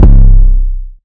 REX 808 1.wav